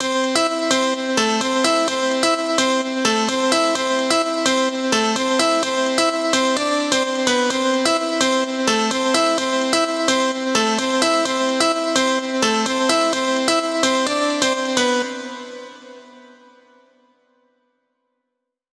VDE 128BPM Notice Melody 1 Root C SC.wav